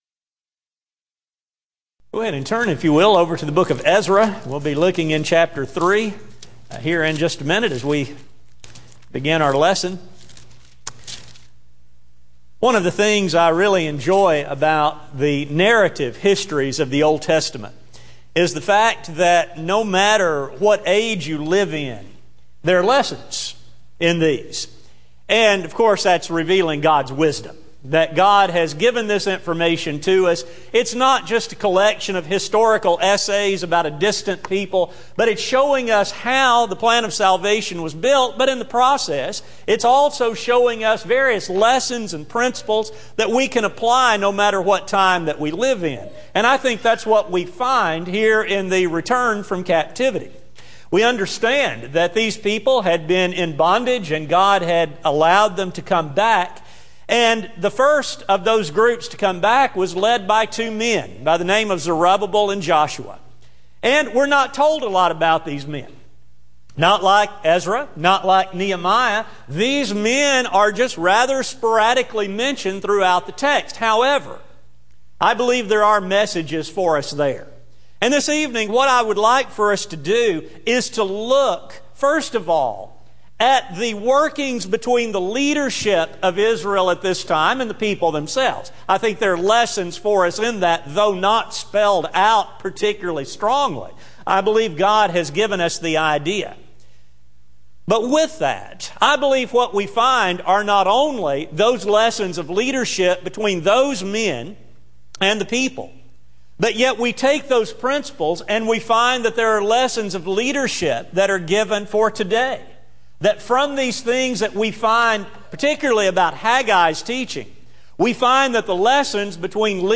Service: Sun PM Type: Sermon